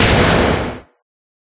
Door8.ogg